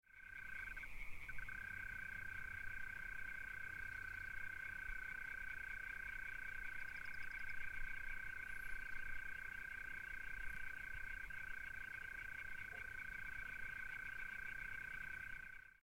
courtilières mêlés à ceux d'Hyla intermedia.
courtiliere-hyla.mp3